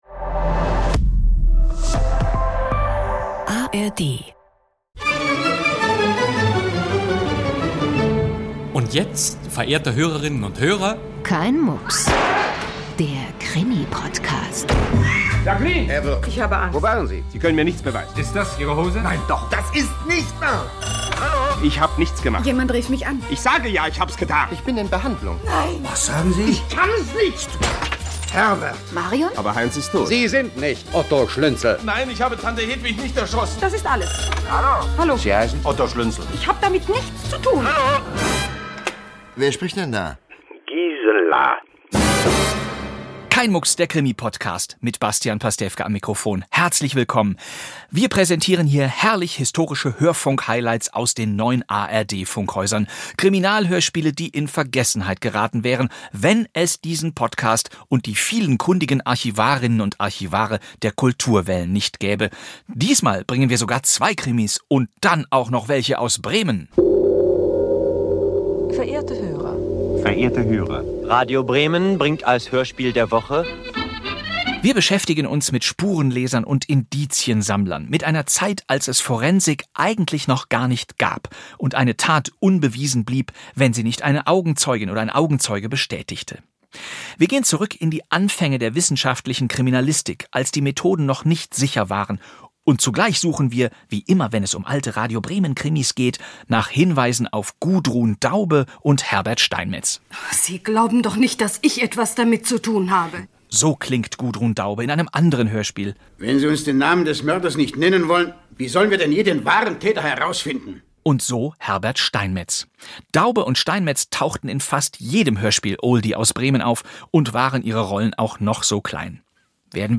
Gleich zwei Hörspiel präsentiert Bastian Pastewka in dieser Kein Mucks-Folge, die sich um True Crime-Fälle dreht.